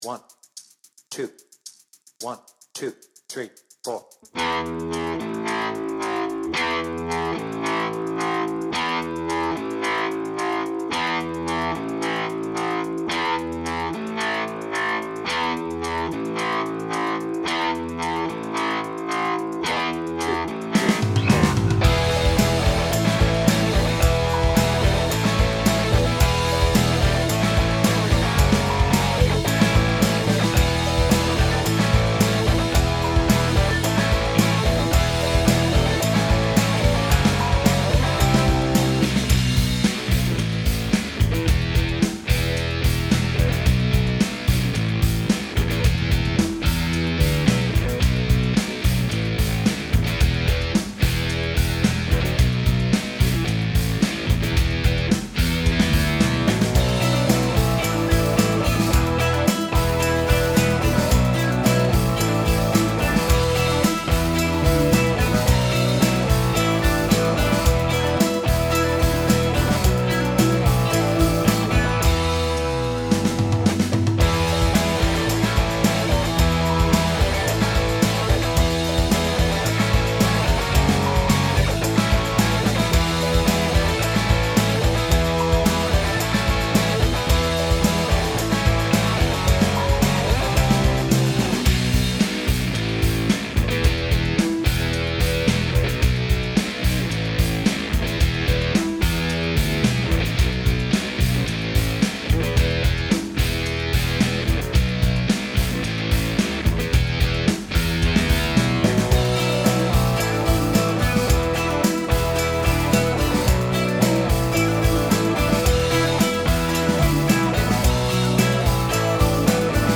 Without vocals